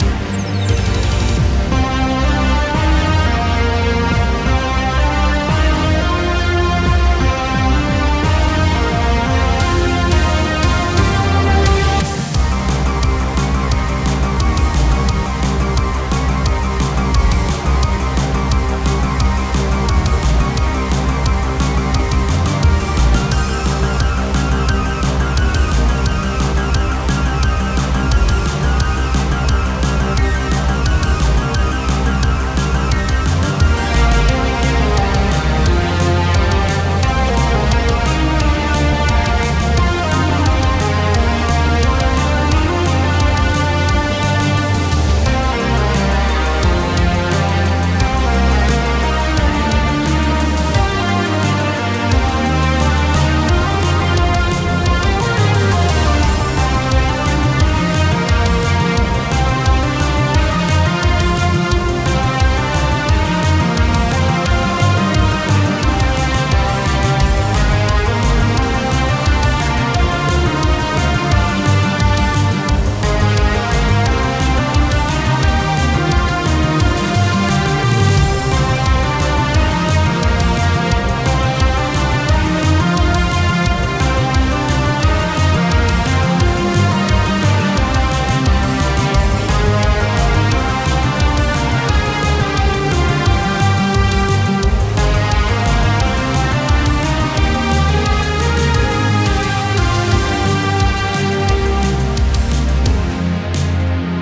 Music for Boss battle theme.